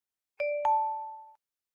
Звуки подключения зарядки